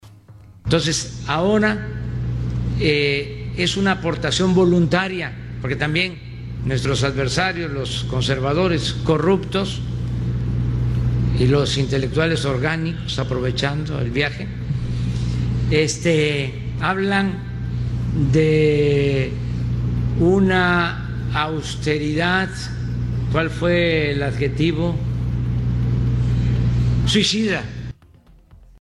En su conferencia de prensa, en las instalaciones de la Sexta Zona Naval de Manzanillo, el presidente López Obrador dijo que el aportará el 25% de salario.